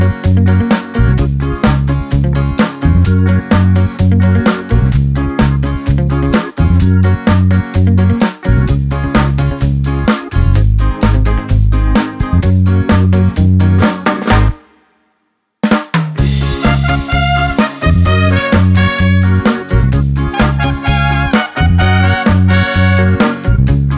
No Backing Vocals Reggae 2:40 Buy £1.50